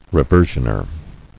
[re·ver·sion·er]